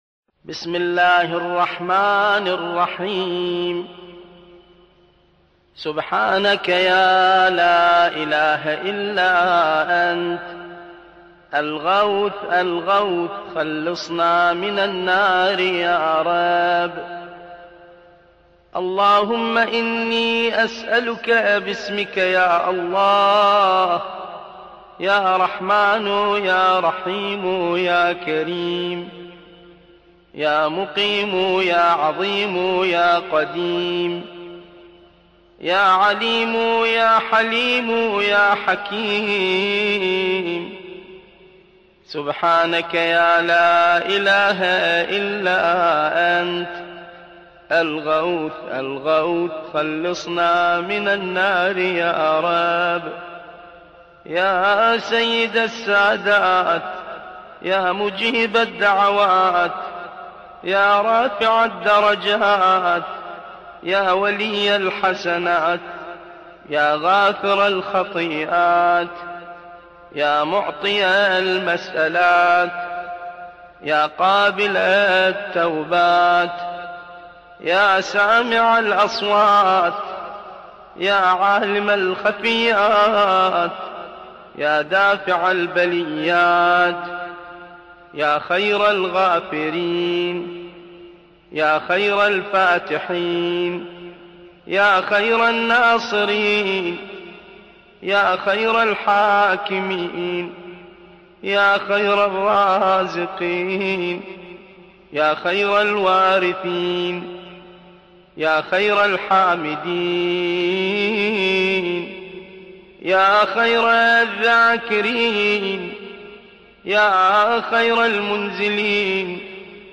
دعاء الجوشن الكبير
الرادود